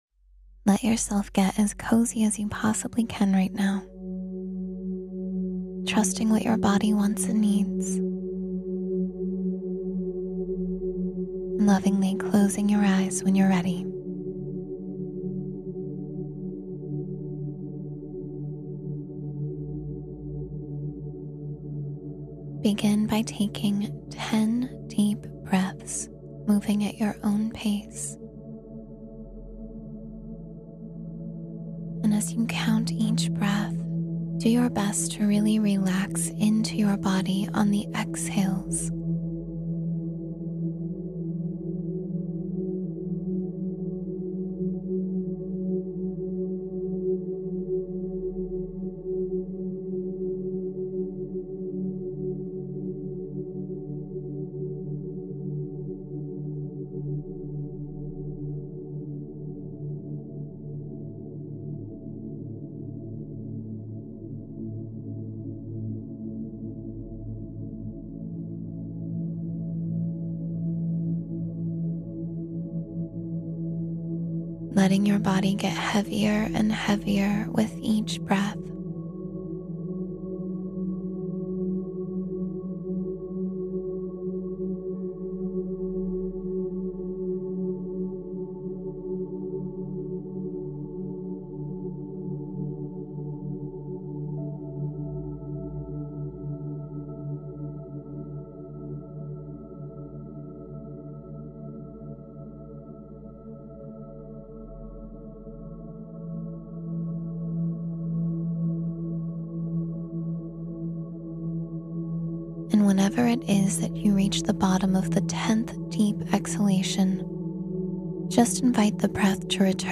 Sleep Deeply and Dream of Love — Guided Meditation for Love and Peaceful Sleep